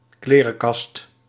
Ääntäminen
Ääntäminen Paris: IPA: [pɑ̃.dʁi] France (Île-de-France): IPA: /pɑ̃.dʁi/ Haettu sana löytyi näillä lähdekielillä: ranska Käännös Ääninäyte Substantiivit 1. klerenkast Suku: f .